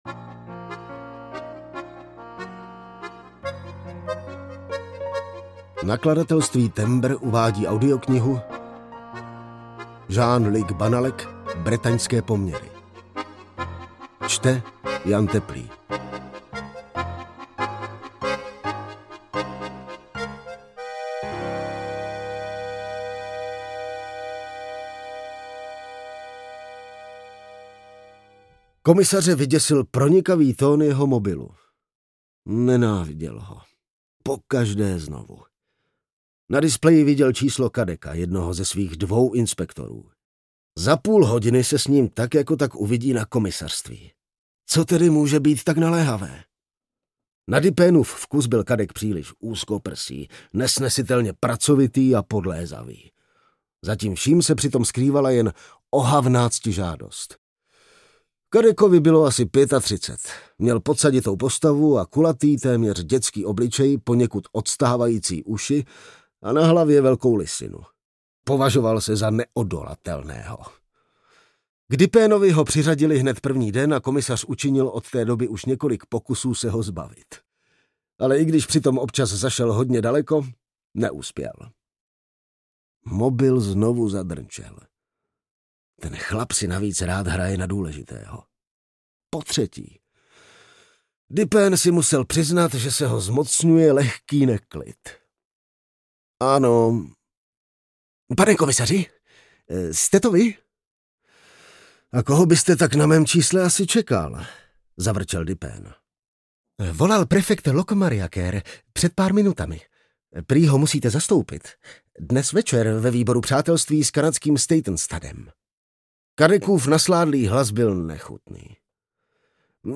Bretaňské poměry audiokniha
Ukázka z knihy
bretanske-pomery-audiokniha